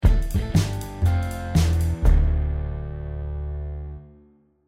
Stinger 2.mp3